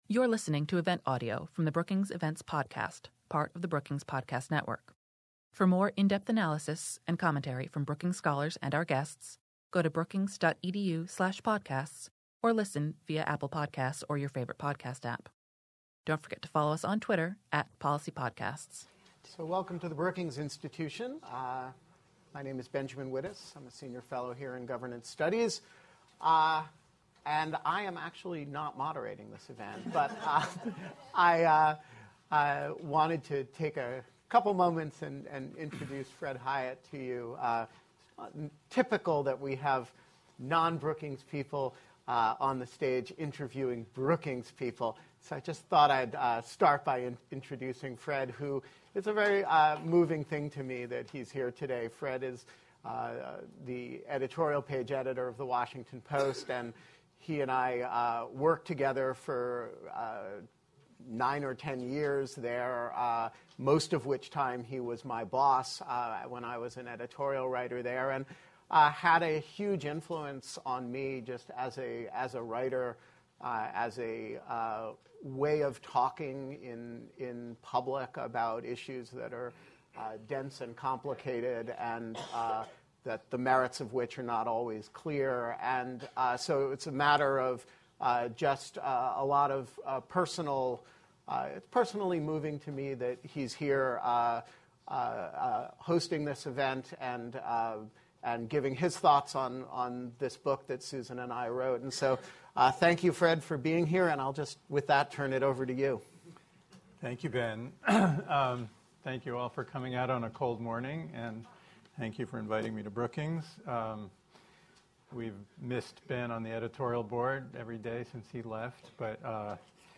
After the discussion, speakers answered questions from the audience.